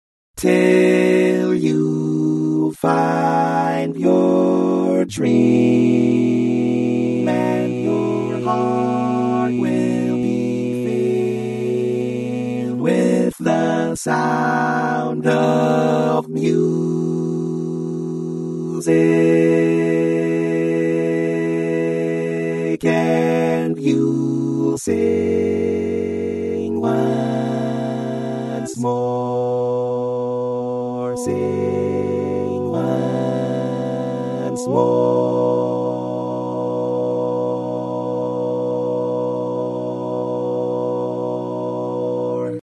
Key written in: B Major
Type: Barbershop